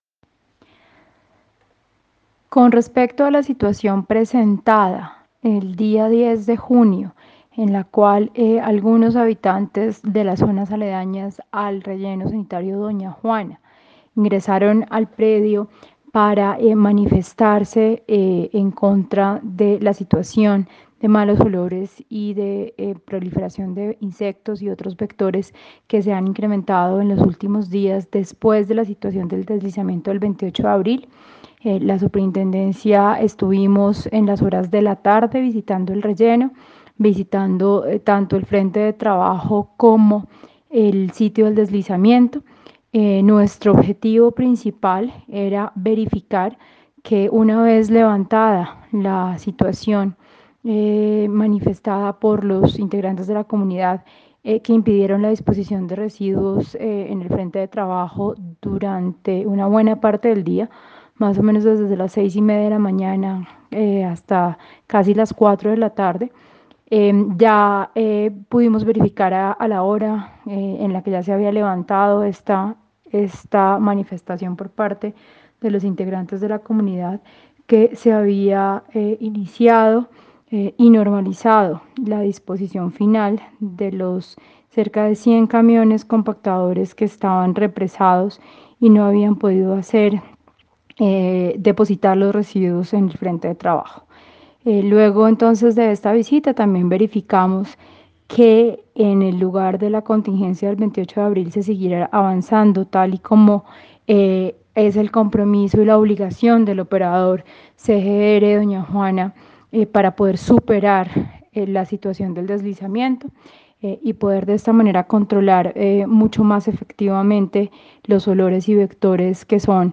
Audio declaraciones Superintendente Natasha Avendaño García
audio_superintendente_navendano-11-jun-20.mp3